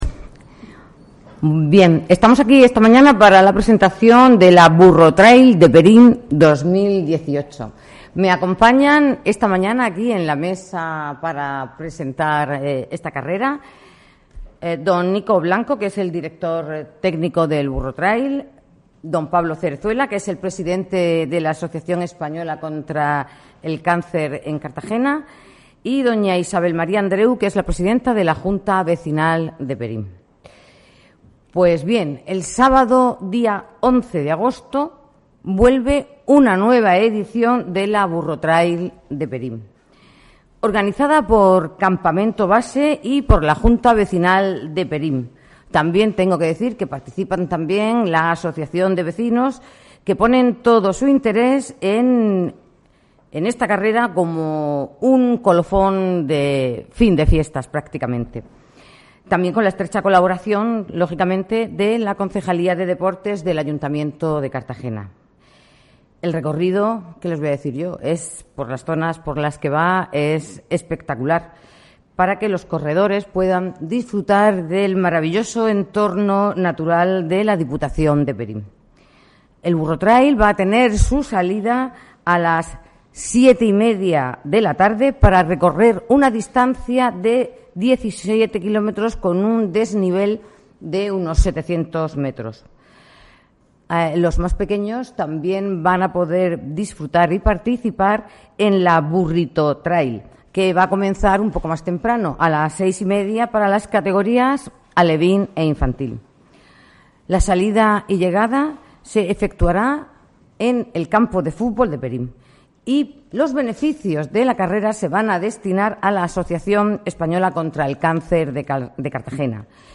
La comparecencia ha corrido a cargo de la concejala de Deportes, Obdulia Gómez